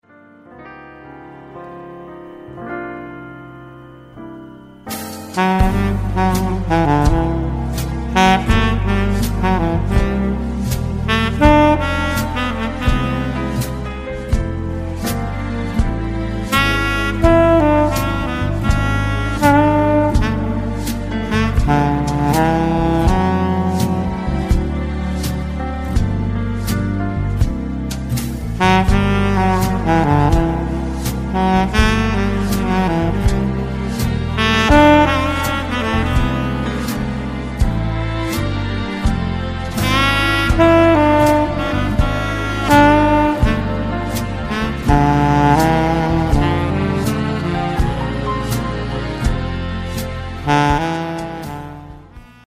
Hörprobe C-Saxophon